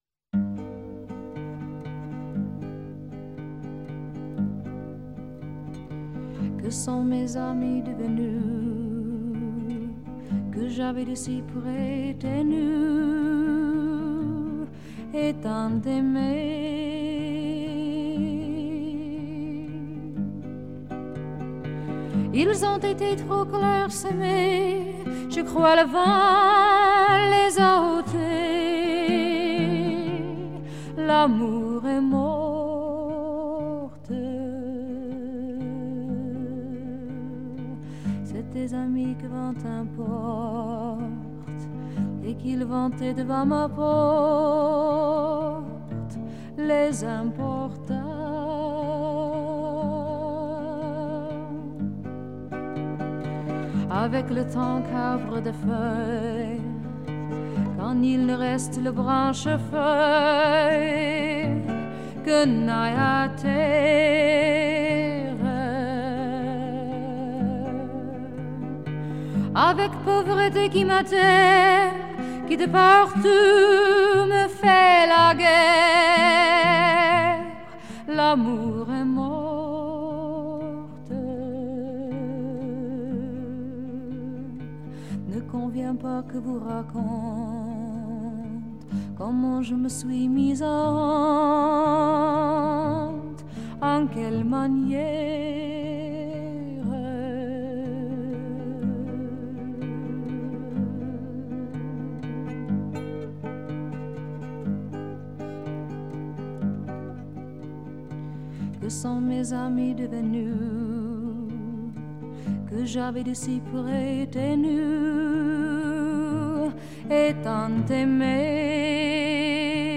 撼動心弦的顫音，悠揚柔軟而充滿強韌勁道的高音演唱